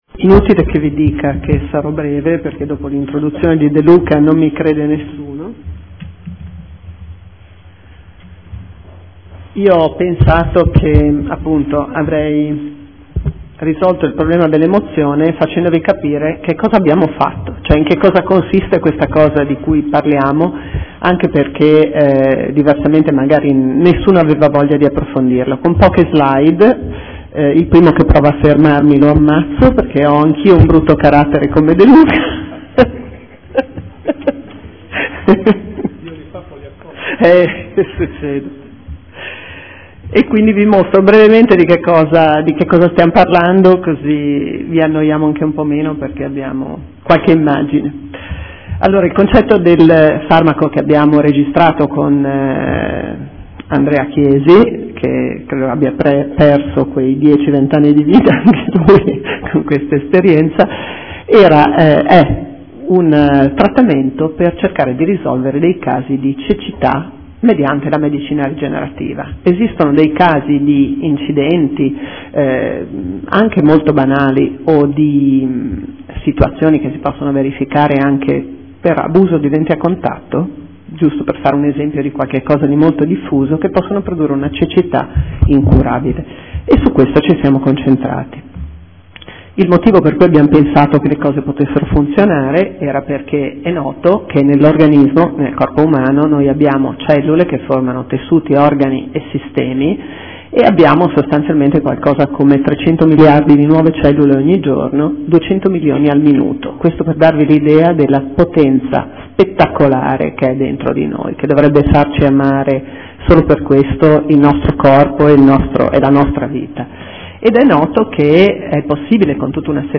Sito Audio Consiglio Comunale